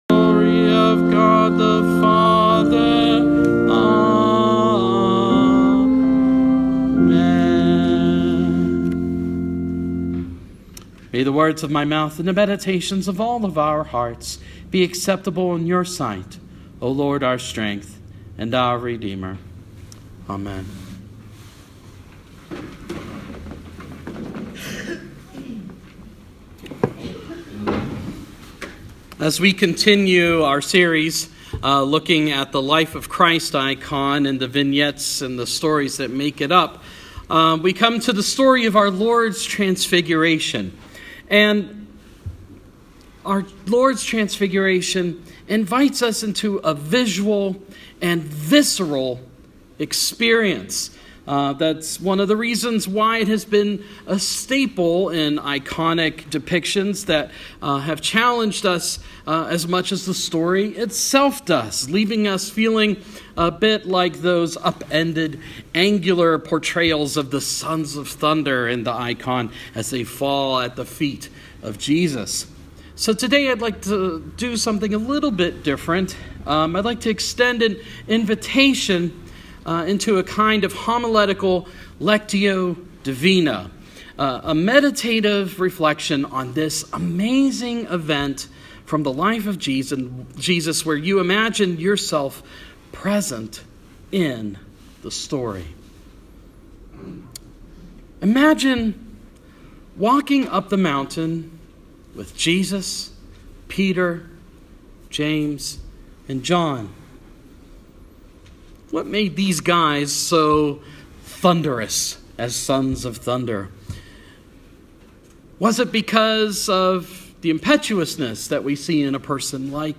Sermon – Life of Christ – The Transfiguration of Our Lord